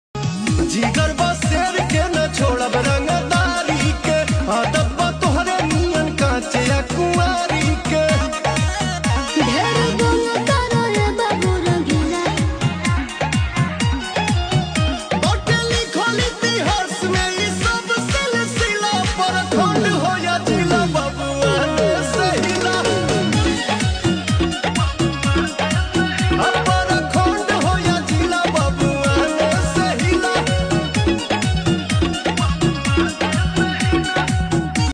Bhojpuri ringtone
powerful music and clear sound quality